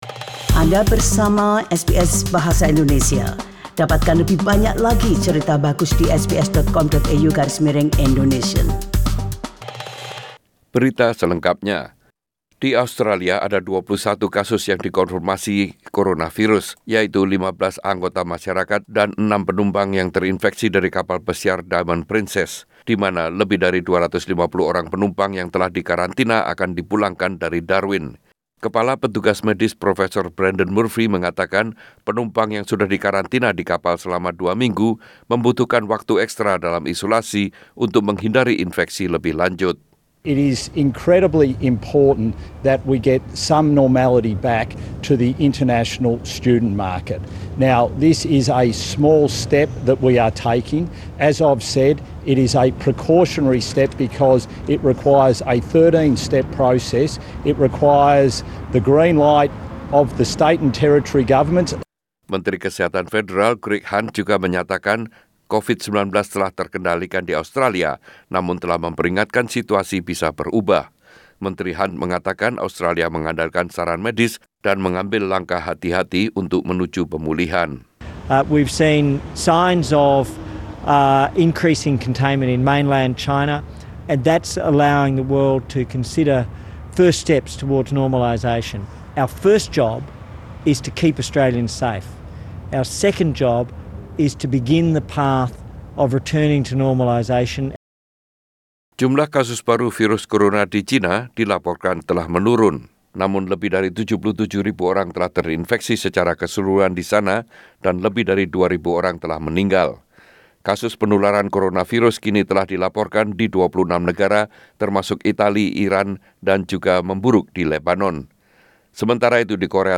Warta Berita Radio SBS Bahasa Indonesia - 23 Feb 2020